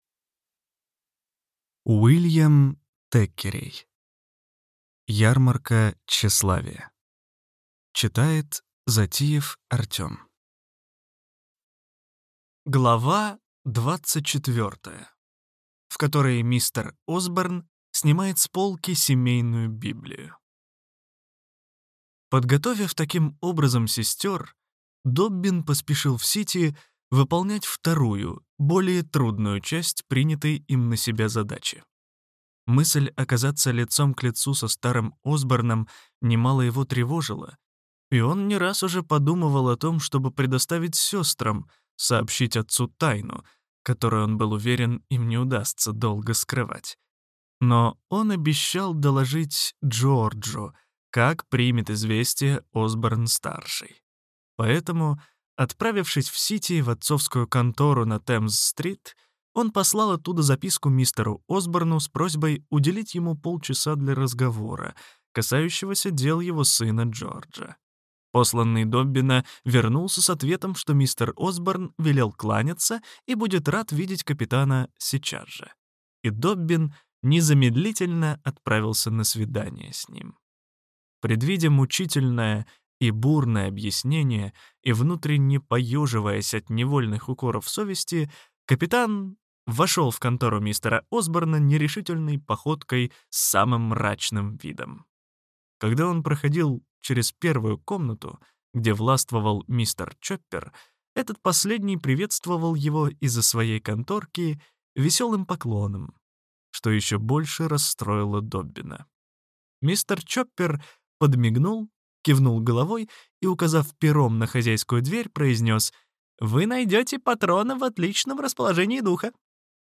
Аудиокнига Ярмарка тщеславия. Часть 2 | Библиотека аудиокниг
Прослушать и бесплатно скачать фрагмент аудиокниги